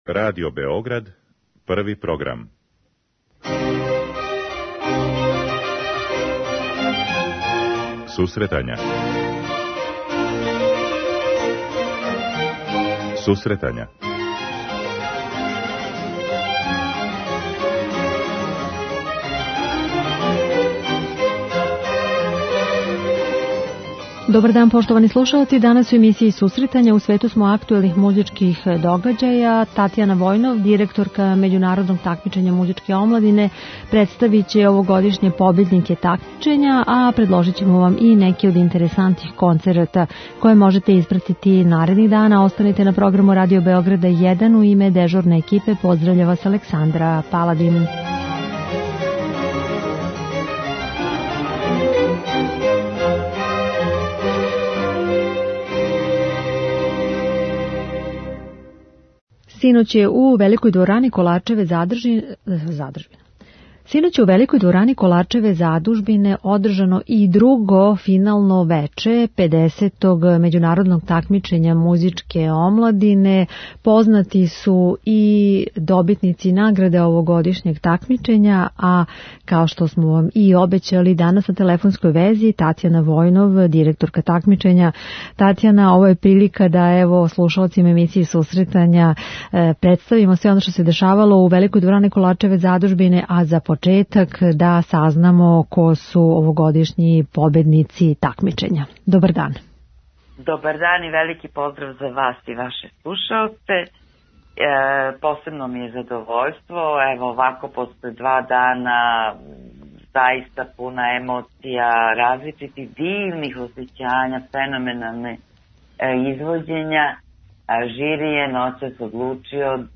Емисија за оне који воле уметничку музику.